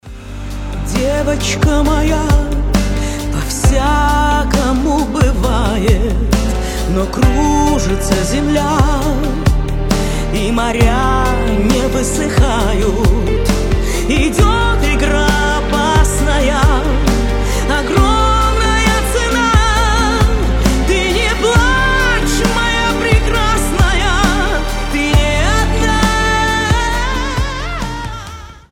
поп
душевные
красивый вокал